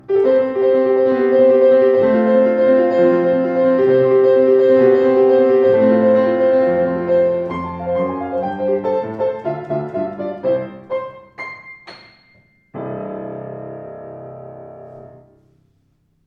Klavier Yamaha YU10 schwarz